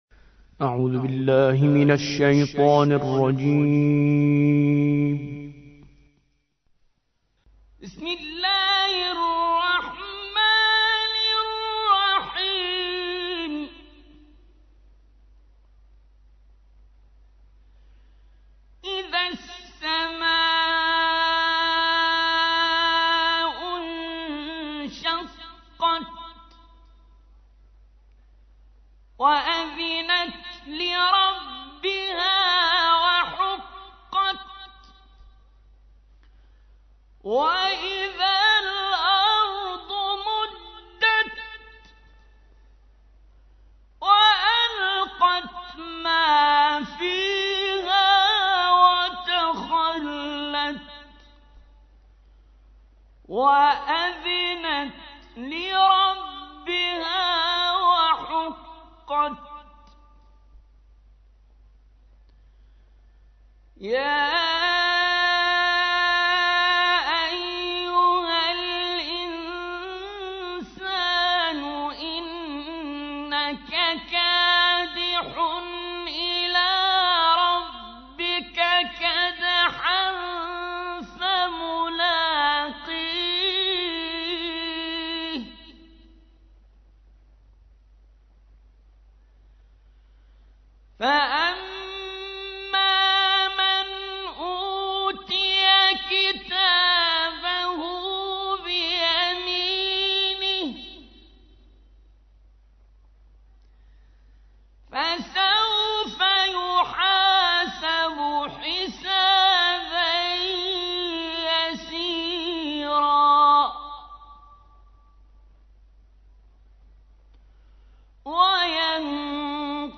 84. سورة الانشقاق / القارئ